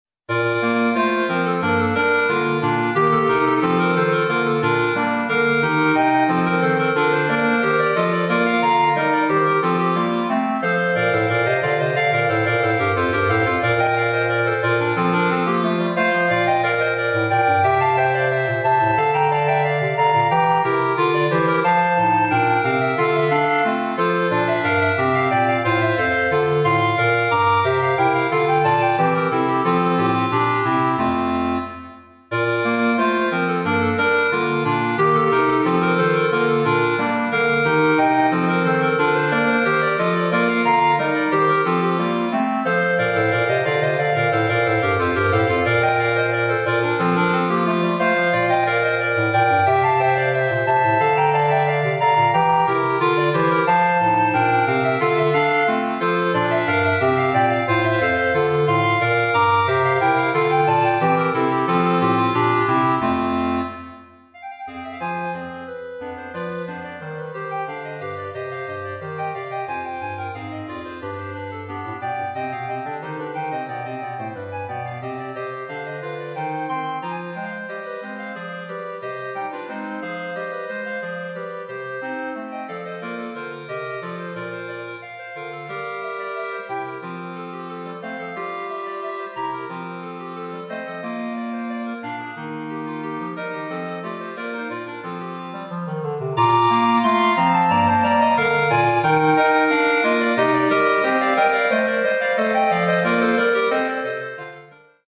Gattung: für Klarinettenquartett
Besetzung: Instrumentalnoten für Klarinette